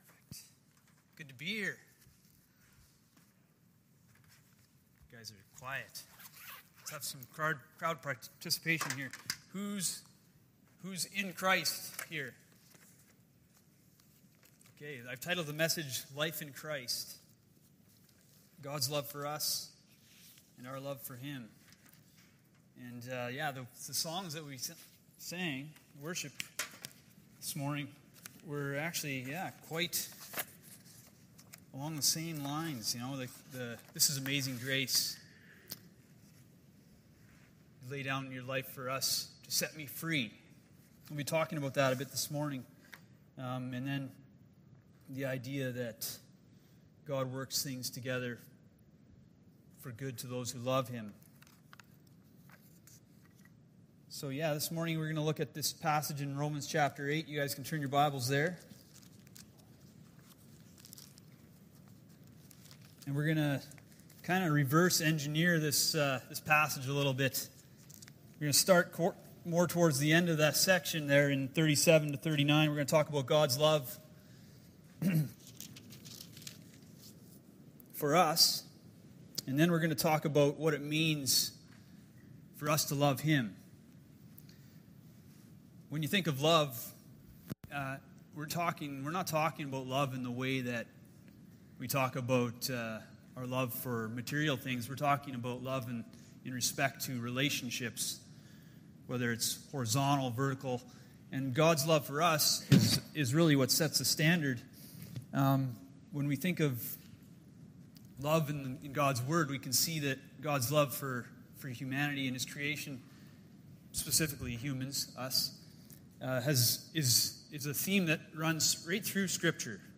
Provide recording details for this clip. Malachi 1:6-2:9 Service Type: Sunday Morning The Best News Ever!